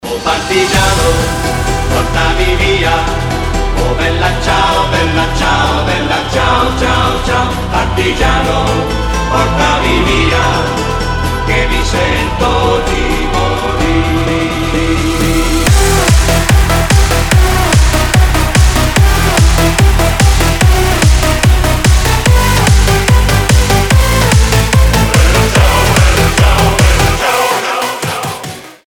• Качество: 320, Stereo
громкие
EDM
electro house